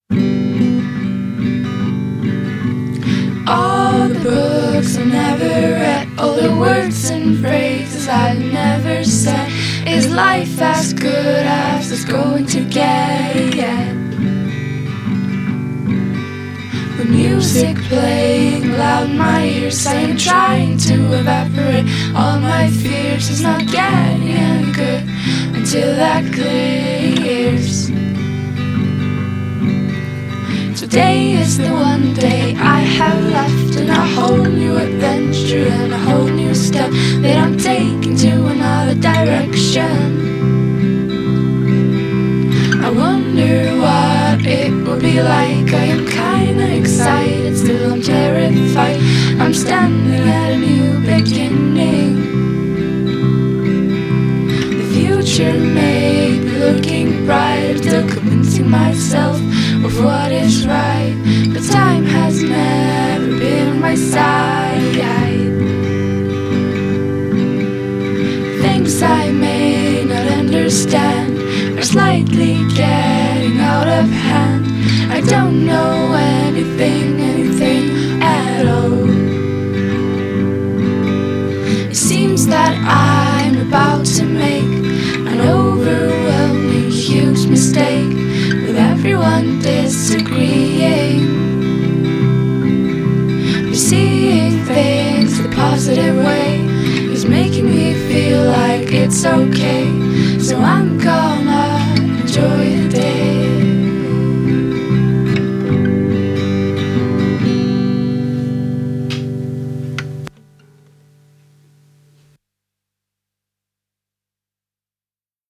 whispery vocals